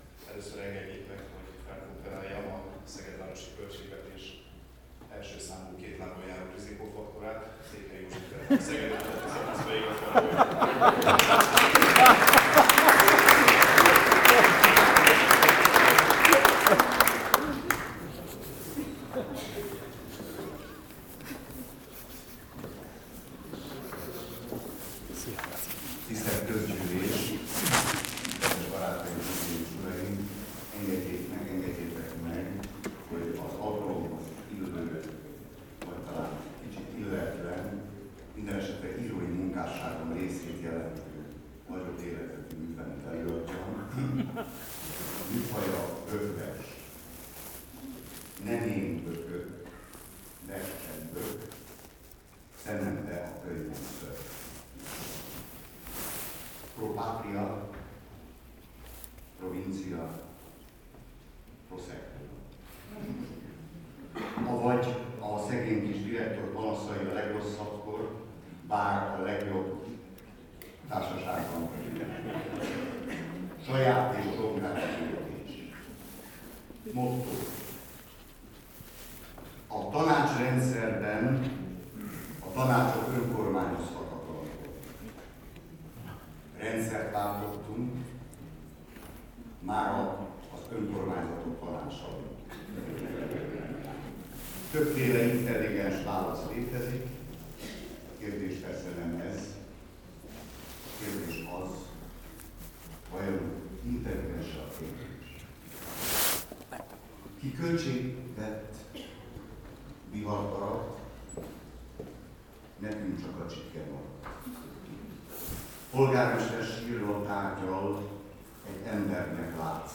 Épp a napokban találtam rá egy 2004-es hangfelvételre, ami nem éppen stúdiófelvétel, mivel alkalmi technikámmal én magam készítettem, de ékes bizonyítéka kifinomult és intelligens humorodnak. Sírtunk-dőltünk mindannyian: Rendkívüli költségvetési közgyűlés - előadják: Botka László polgármester, Székhelyi József és Gregor József...